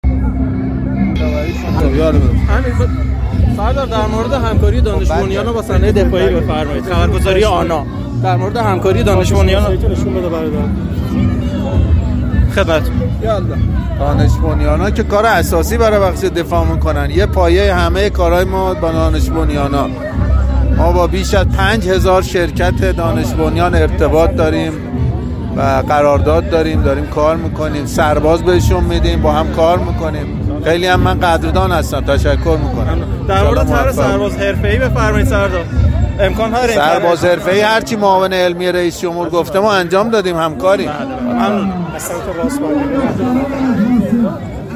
سرلشکر باقری در گفت‌وگو با آنا:
سردار سرلشکر محمد باقری رئیس ستاد کل نیرو‌های مسلح در حاشیه راهپیمایی ۲۲ بهمن در گفت‌وگو با خبرنگاراقتصادی خبرگزاری علم و فناوری آنا در خصوص همکاری صنایع دفاعی کشور با شرکت‌های دانش‌بنیان اظهار کرد: شرکت‌های دانش‌بنیان‌ها کار اساسی برای بخش دفاع انجام می‌دهند.